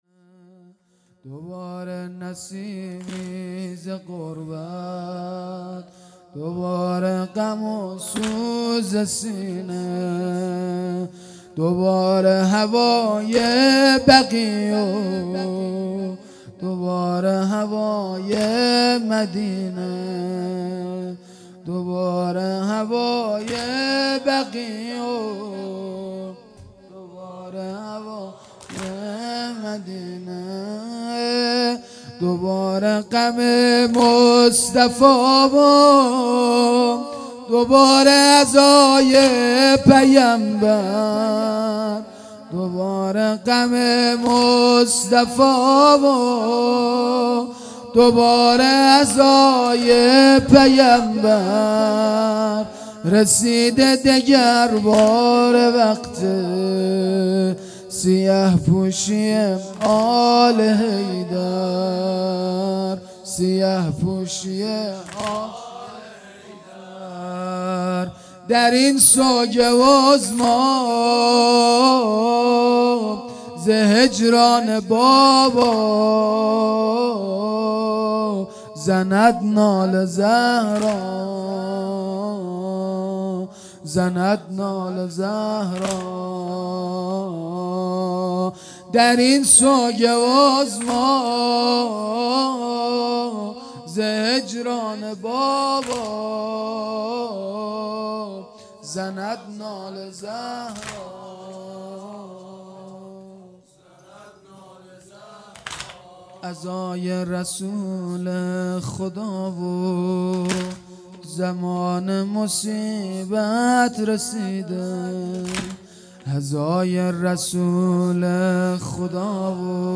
دوباره نسیمی ز غربت دوباره غم و سوز سینه _ زمینه